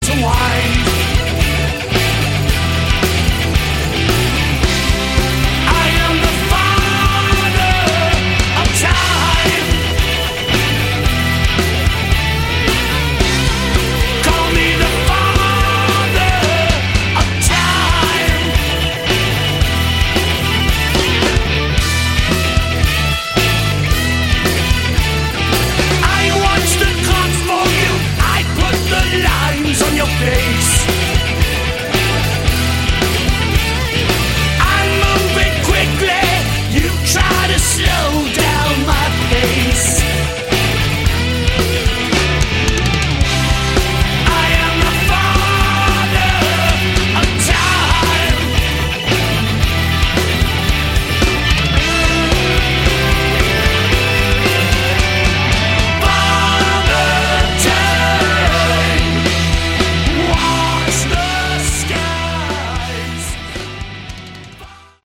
Category: Melodic Metal
all vocals and instruments